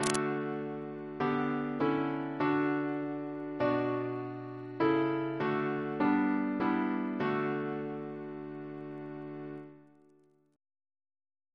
Single chant in D Composer: Charles Steggall (1826-1905) Reference psalters: OCB: 97